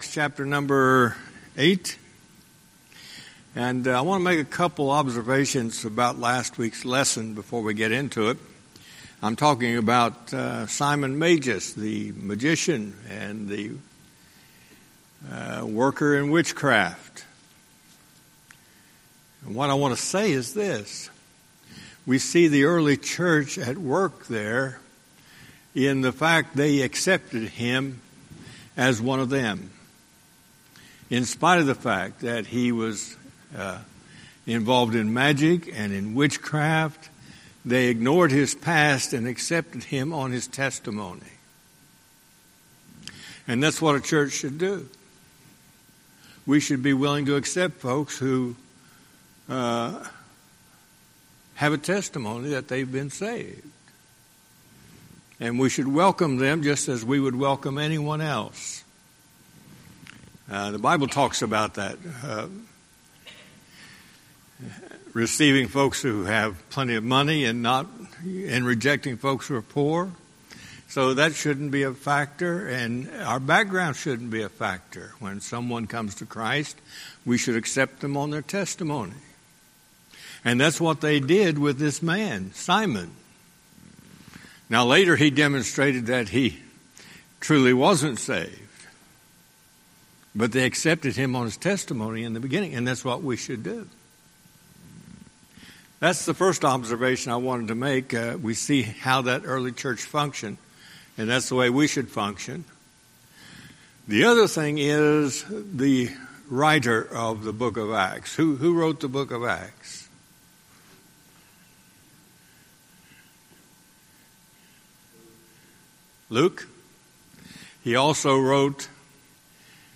Sermons
Email Details Series: Guest Speaker Date